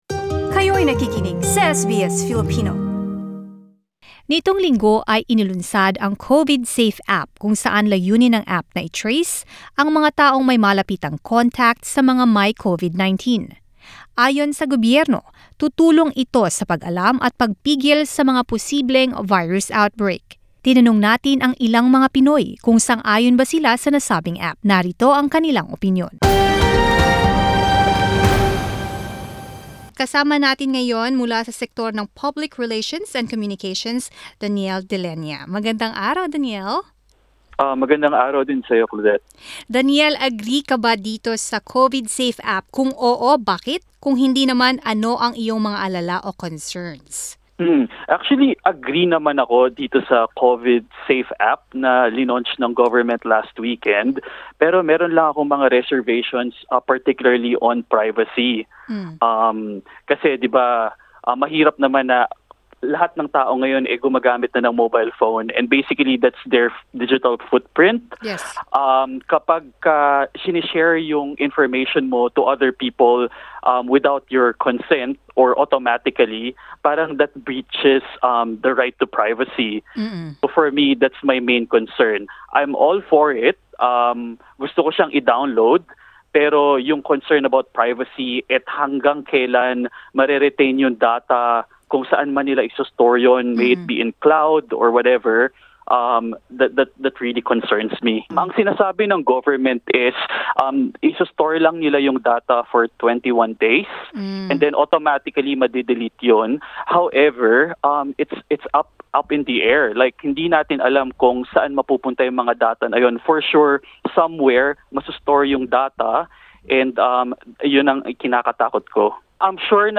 Vox Pop: What's your opinion about the COVIDSafe app?
We spoke to Filipinos in Australia what they think about the new CovidSafe app. Whilst some see the app as a good tool to help 'flatten the curve' and slow the rate of transmissions, others are concerned about data privacy.